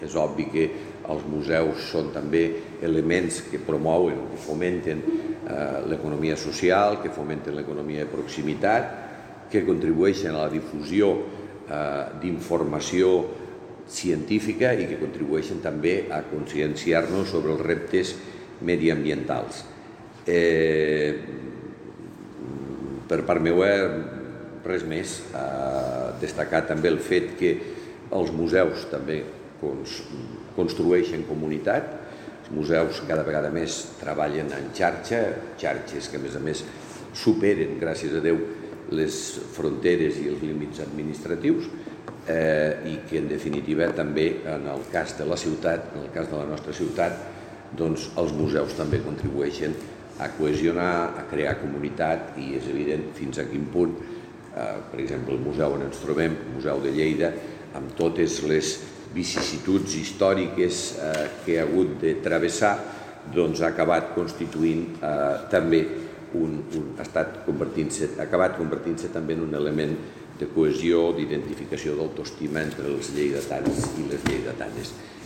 tall-de-veu-del-paer-en-cap-miquel-pueyo-sobre-la-nit-dels-museus-2022